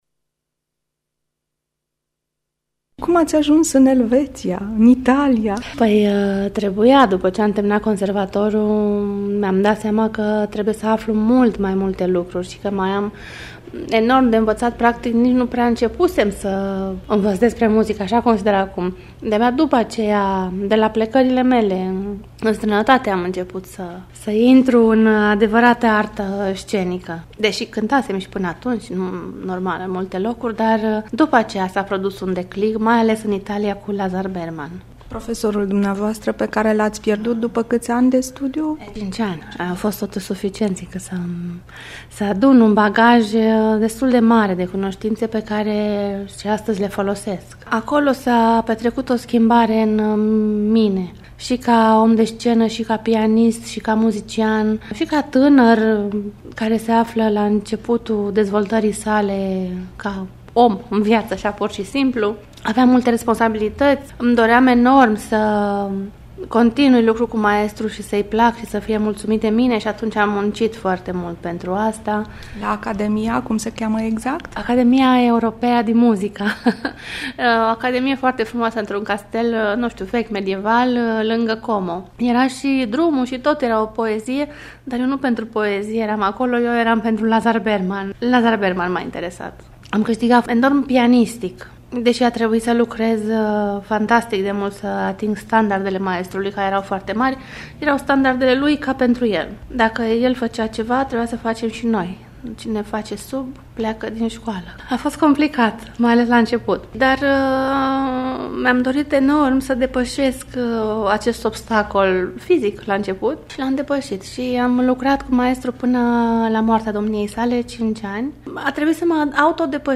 de vorbă cu pianista